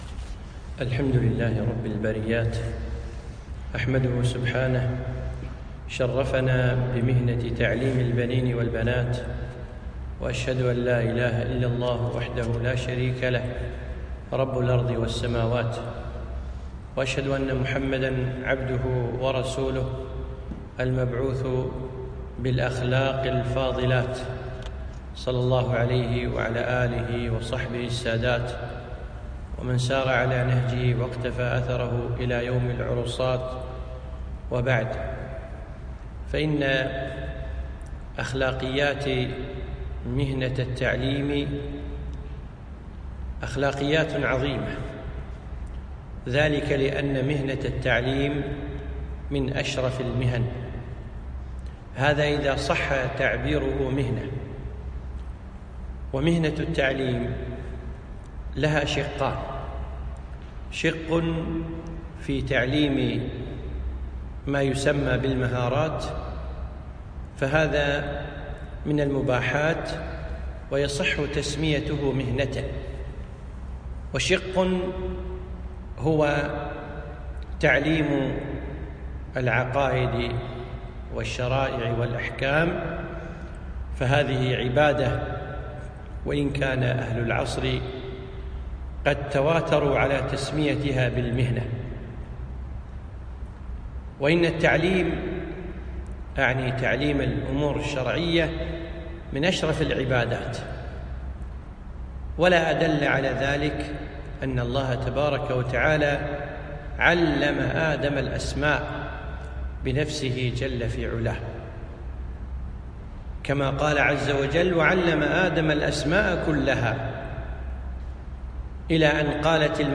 محاضرة - أخـلاقيات المهنة